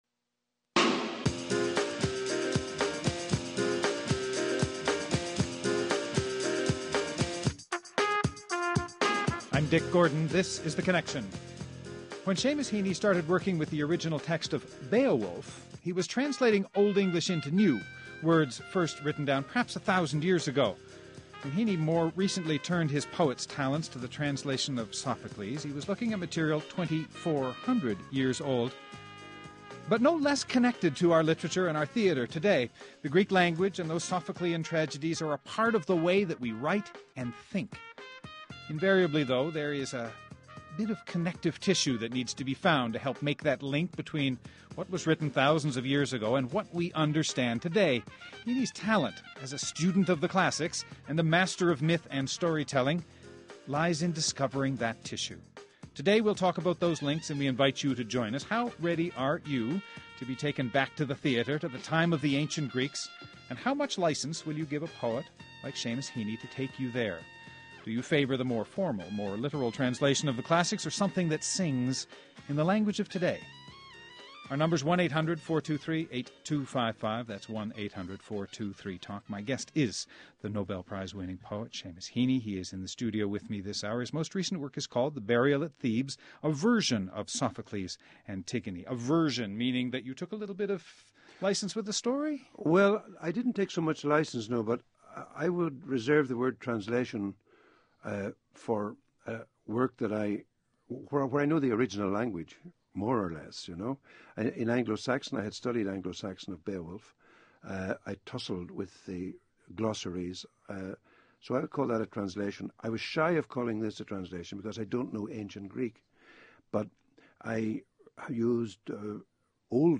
Guests: Seamus Heaney, winner of the Nobel Prize in Literature and author of numerous books of poetry, prose, and criticism.